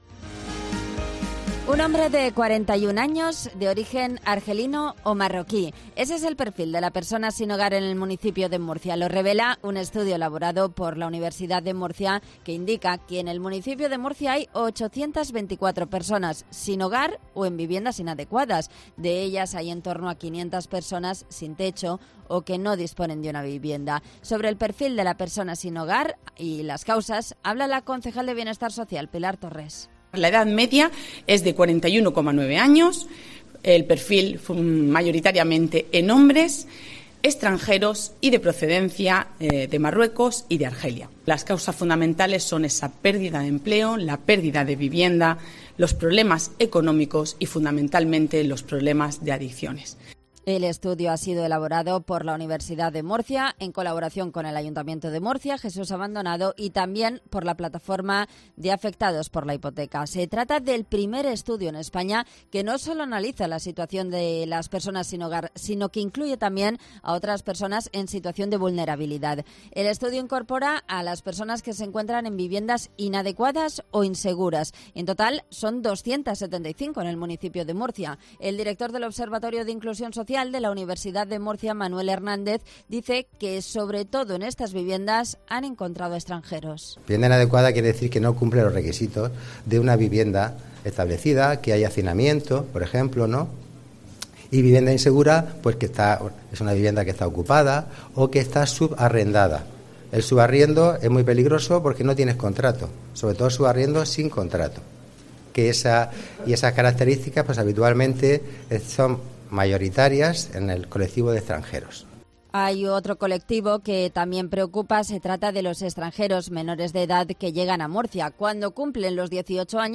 Reportaje Día Mundial de personas sin Hogar